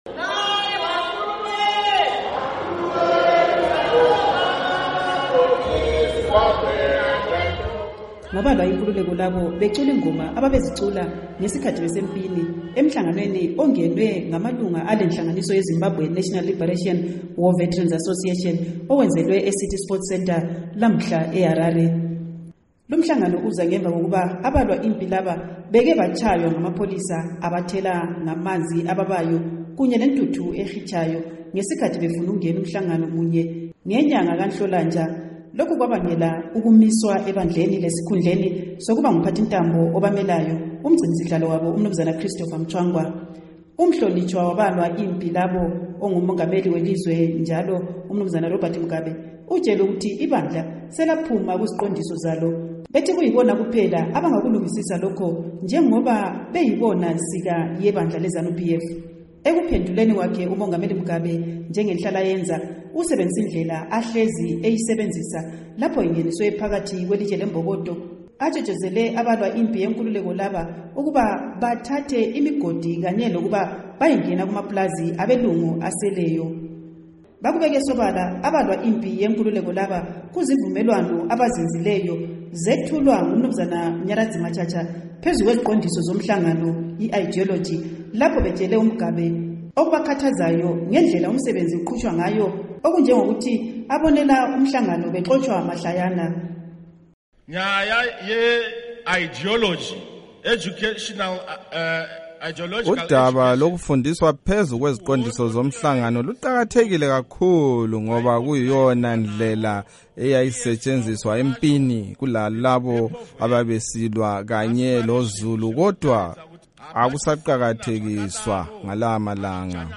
Ngabalwa inkululeko labo becula ingoma ababezicula ngesikhathi besempi, emhlanganweni ongenwe ngamalunga ale nhlanganiso ye Zimbabwe Nationa Leberation War Veterans Association, owenzelwe eCity Sports Centre lamuhla.